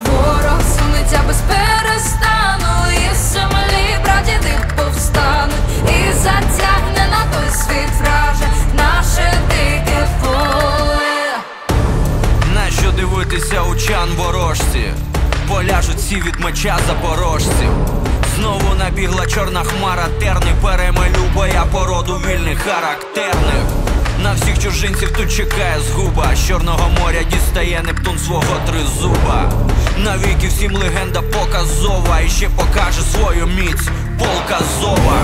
• Качество: 320, Stereo
мужской голос
спокойные
красивый женский голос